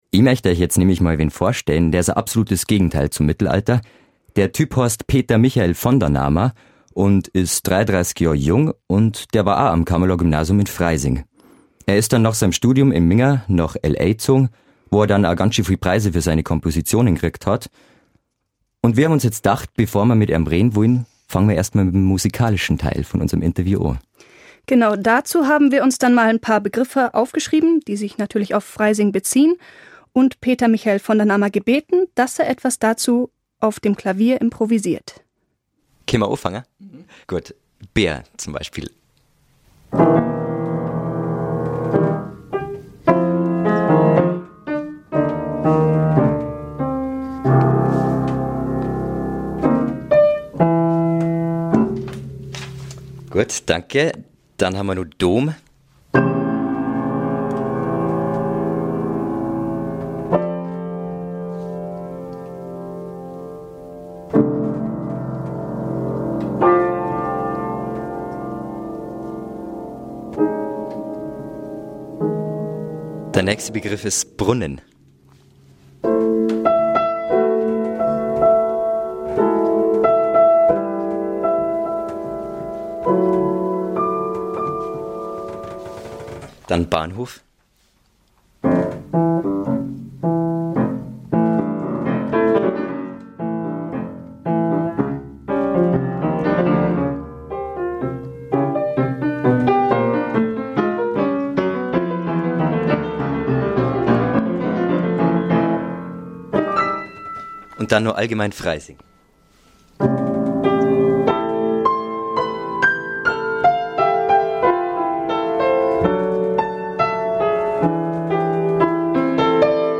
About: A musical interview